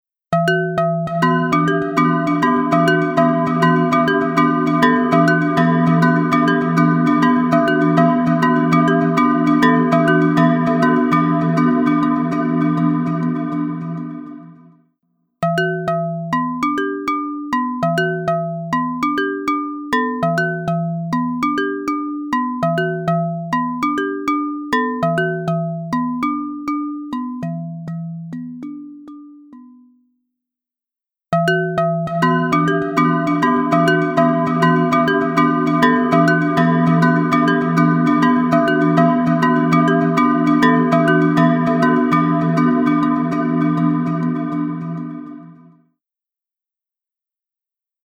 Massive Otherworldly Reverb
Blackhole | Percussion | Preset: EchoVerb
Blackhole-Eventide-Pitched-Percussion-Synth-EchoVerb.mp3